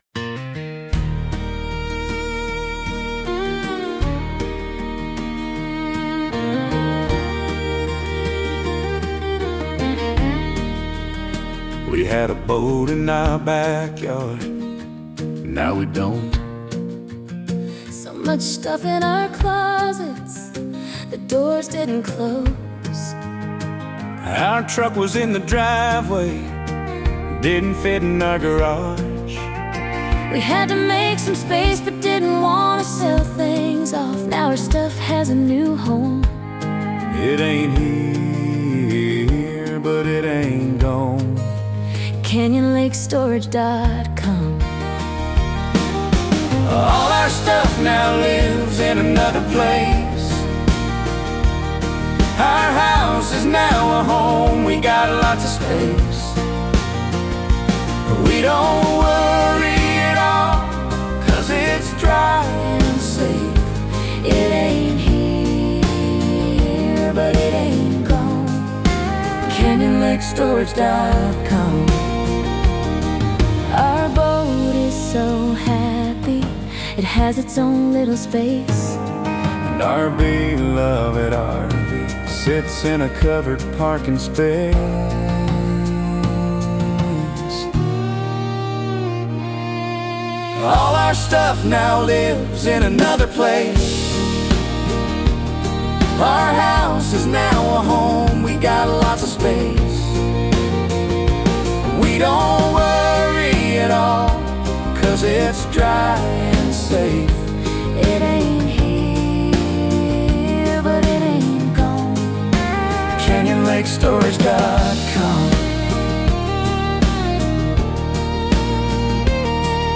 CLS-Song-Duet-Perfect.mp3